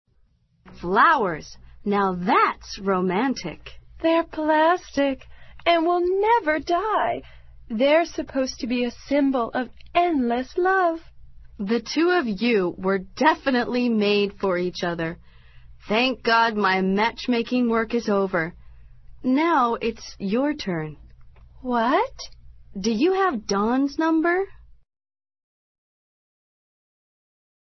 网络社交口语对话第72集： 这才叫浪漫